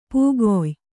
♪ pōgoy